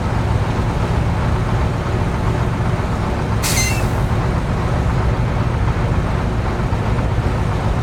train-engine-2.ogg